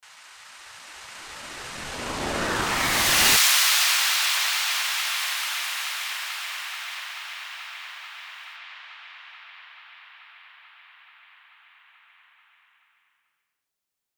FX-1039-TRANSITION-WHOOSH
FX-1039-TRANSITION-WHOOSH.mp3